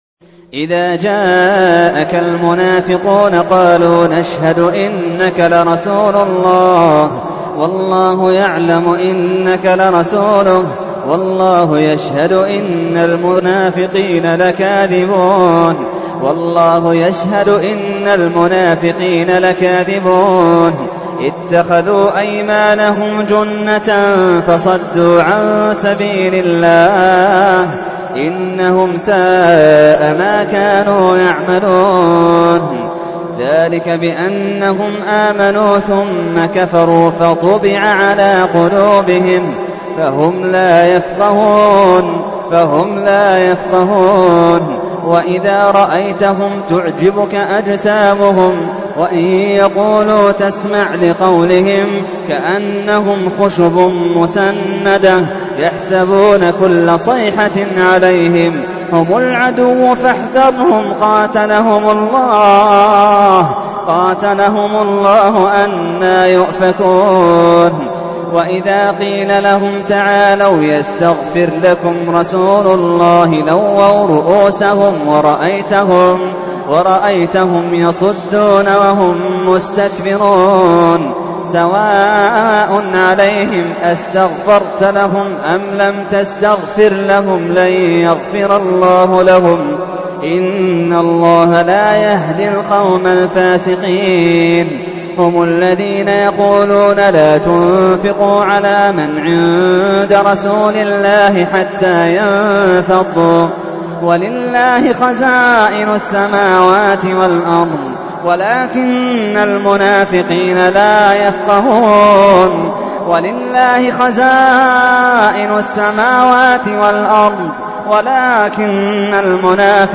تحميل : 63. سورة المنافقون / القارئ محمد اللحيدان / القرآن الكريم / موقع يا حسين